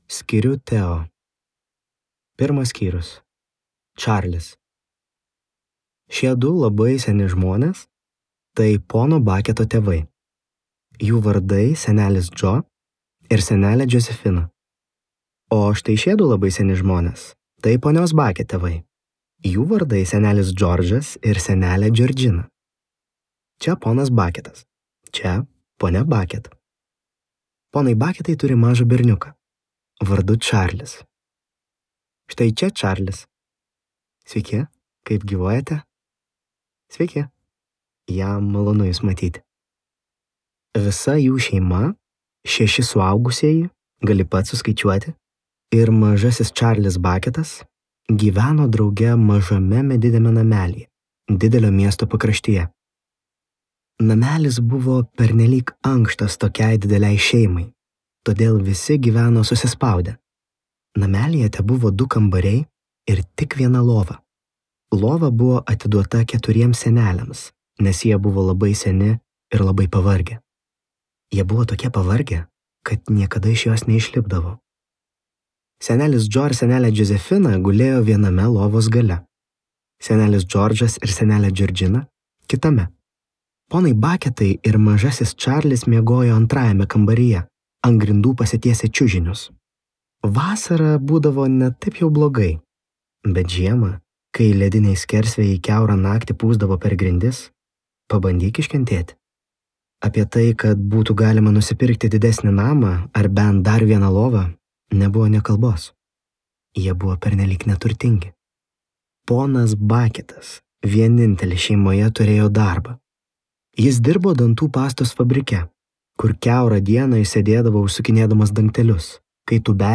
Čarlis ir šokolado fabrikas | Audioknygos | baltos lankos